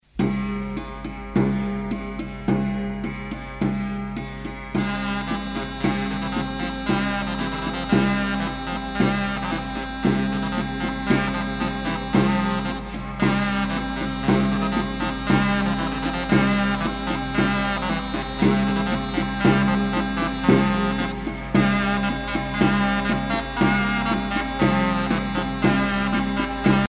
danse : branle
Pièce musicale éditée